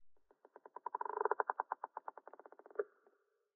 creaking_heart_idle1.ogg